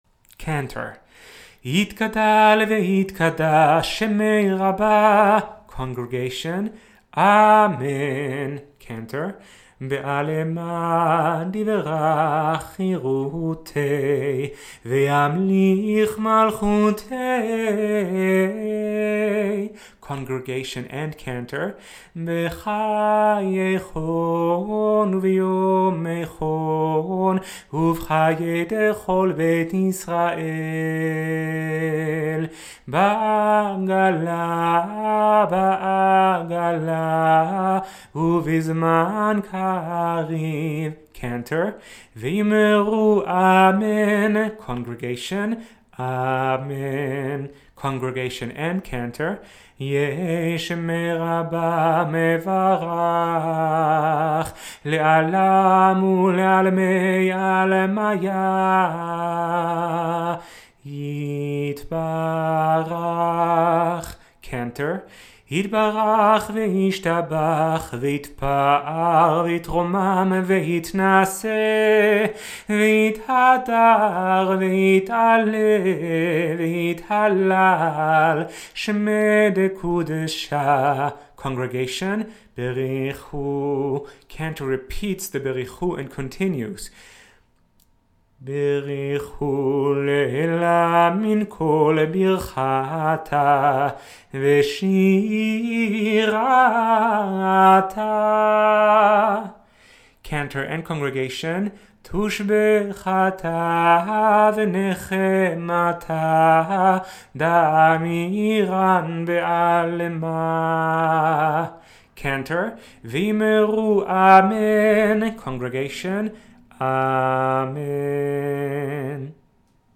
We hope this page, which will be regularly updated, can be a support for those who are interested in getting acquainted with some congregational tunes as well as chants in the traditional mode for Friday Night Shabbat Service.
Friday Night Music